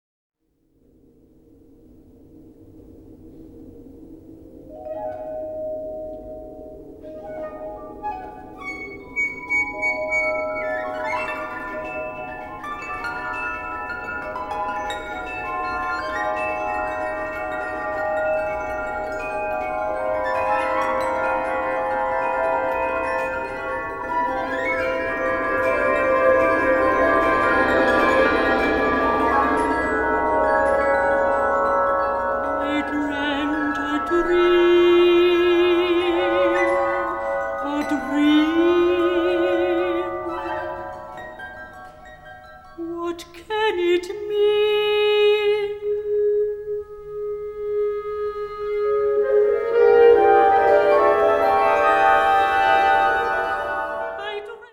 Categoría Banda sinfónica/brass band
Subcategoría Música contemporánea original (siglo XX y XXI)
Instrumentación/orquestación Ha (banda de música)